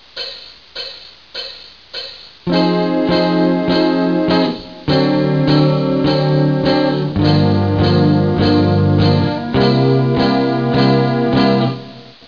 Я попытался исполнить это в "Manhattan" (Venus Isle), в особенности над прогрессией Ebm7-C7-Fm7-Bb7 (смотрите ПРИМЕР 1).